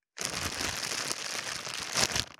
2025年3月1日 / 最終更新日時 : 2025年3月1日 cross 効果音
626コンビニ袋,ゴミ袋,スーパーの袋,袋,買い出しの音,ゴミ出しの音,袋を運ぶ音,